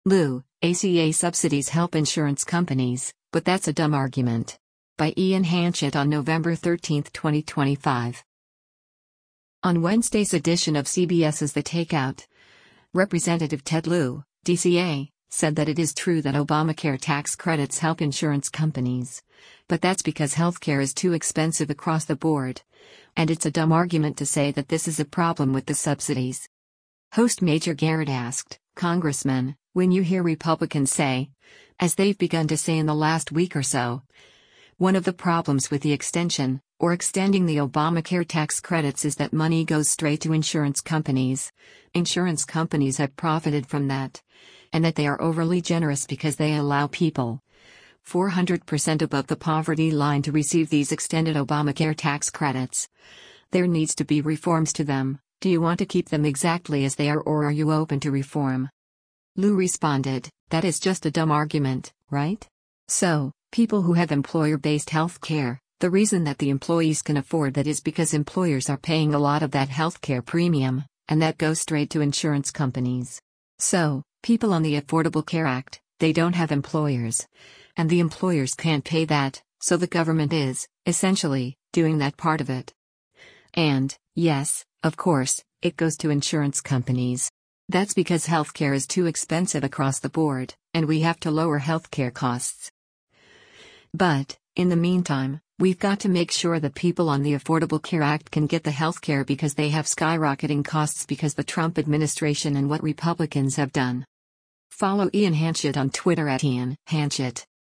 On Wednesday’s edition of CBS’s “The Takeout,” Rep. Ted Lieu (D-CA) said that it is true that Obamacare tax credits help insurance companies, but “That’s because health care is too expensive across the board,” and it’s “a dumb argument” to say that this is a problem with the subsidies.